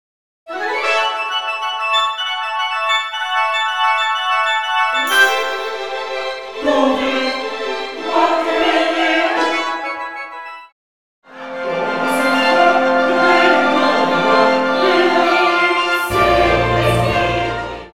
(WITH CHORUS)